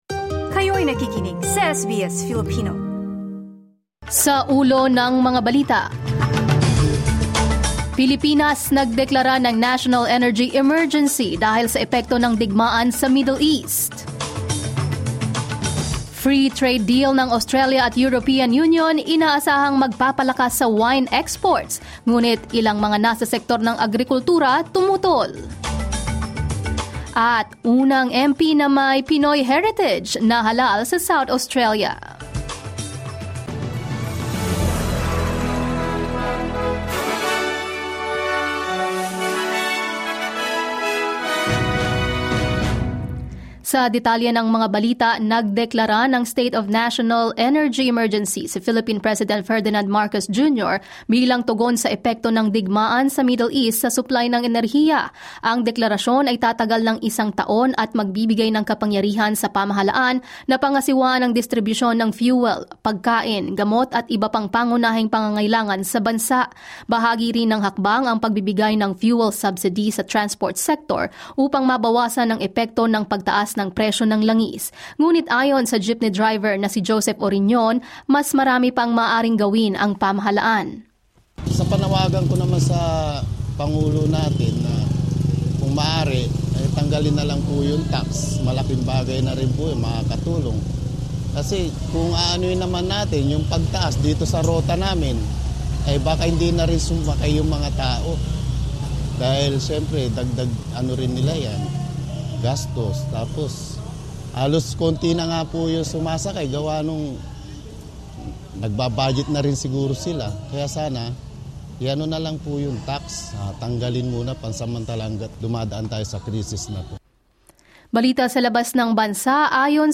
SBS News in Filipino, Wednesday 25 March 2026